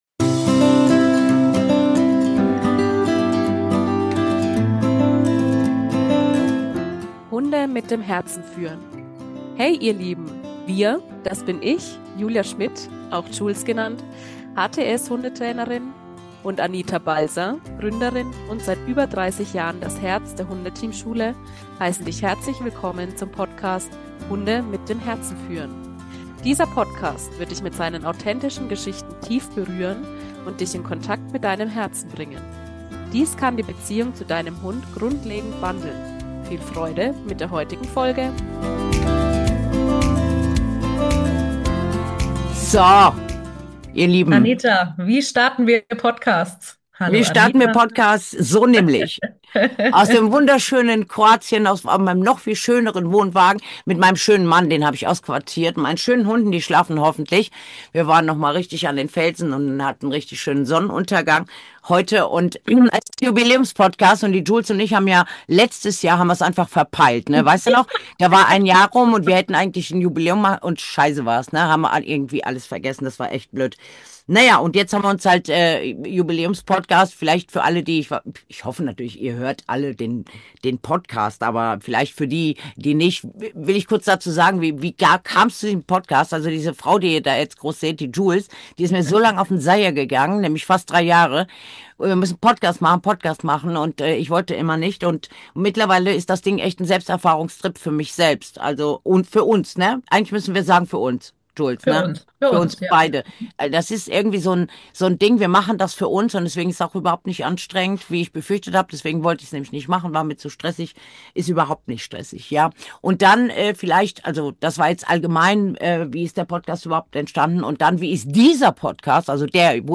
Mehr als 250 Menschen waren live dabei, knapp 2000 haben die YouTube-Aufzeichnung gesehen – und die Resonanz zeigt: Dieses Thema bewegt uns alle. Wir tauchen ein in die Welt der Hunde, in ihre Bedürfnisse und unsere Verantwortung als Menschen.